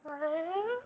meow7.wav